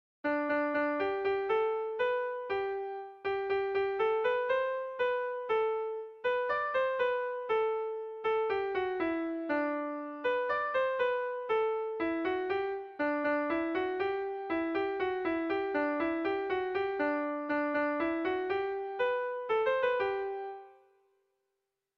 Otxagabian zer dute - Air de bertsos - BDB.
Irrizkoa
ABD